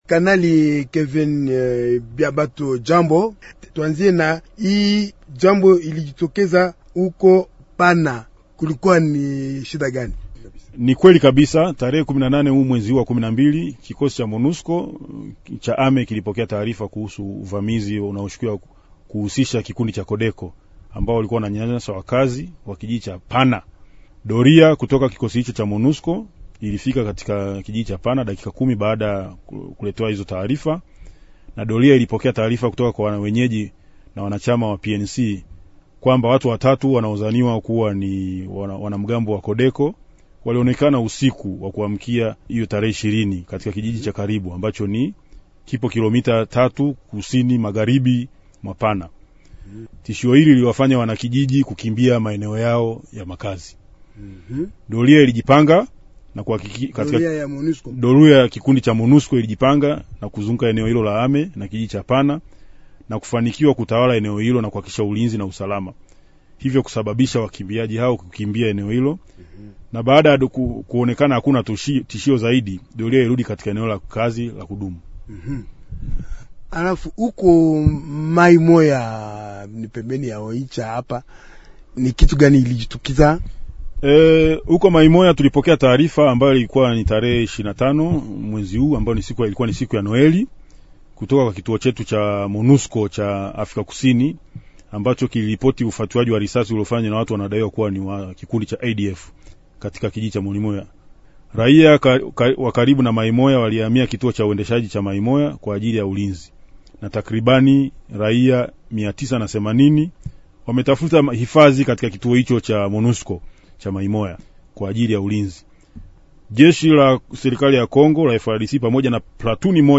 Kando ya hiyo, anarejea ukweli kwamba kikosi cha Umoja wa Mataifa kilizuia, na FARDC, uvamizi wa wapiganaji wenye silaha katika vijiji kadhaa waliotaka kuvuruga usalama wa eneo hilo. Mahojiano